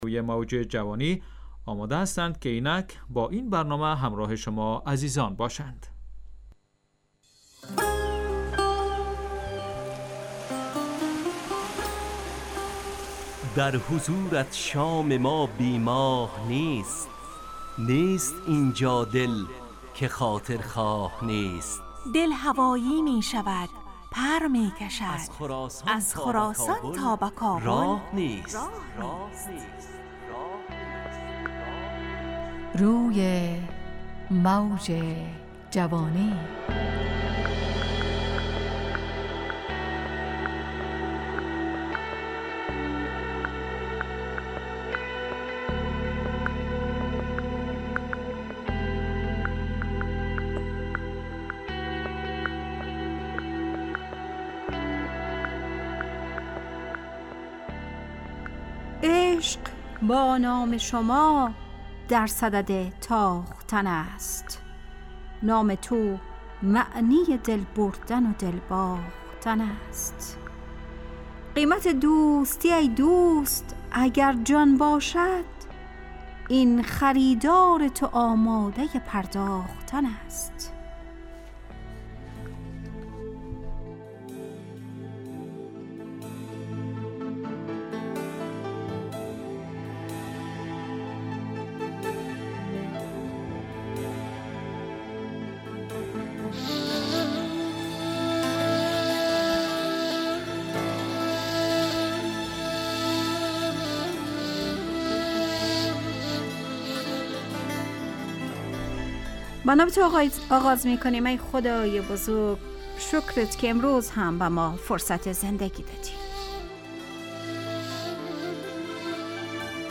همراه با ترانه و موسیقی مدت برنامه 70 دقیقه . بحث محوری این هفته (دل)